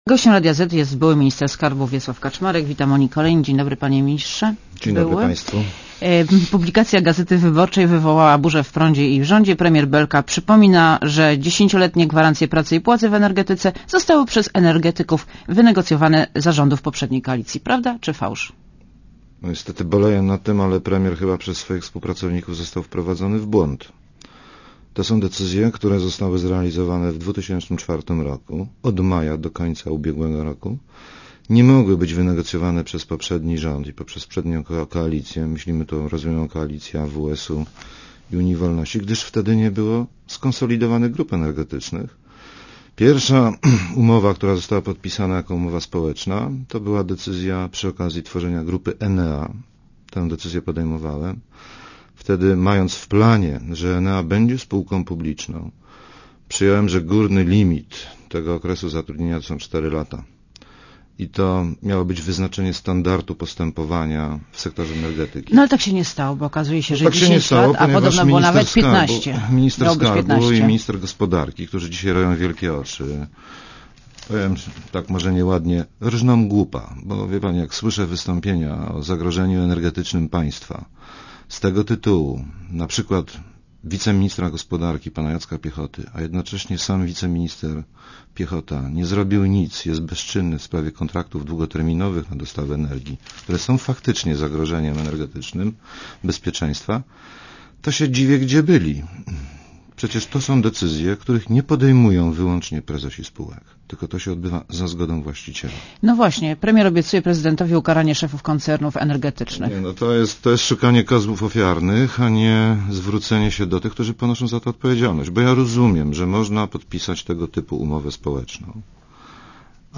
Posłuchaj Wiesława Kaczmarka